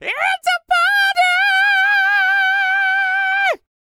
DD FALSET040.wav